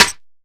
DillaClackin.wav